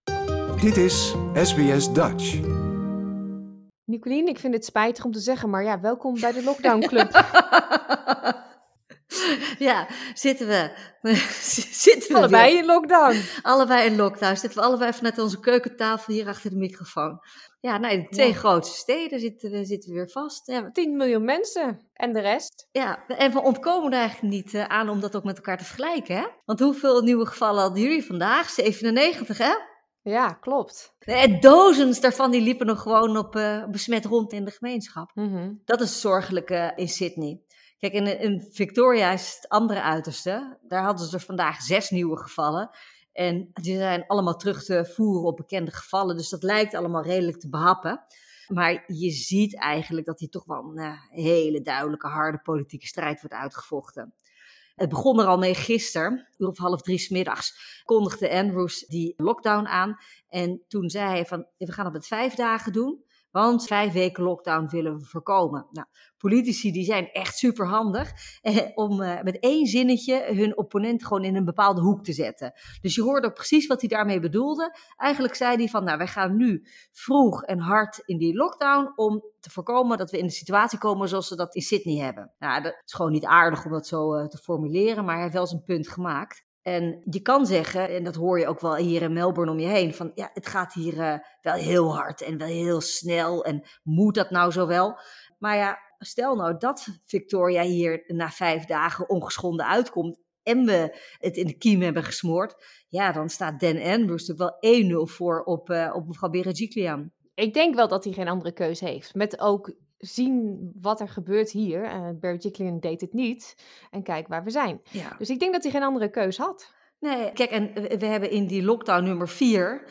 Zijn aanpak is bijna het tegenovergestelde van dat van de Gladys Berejiklian, de premier van New South Wales. Politiek commentator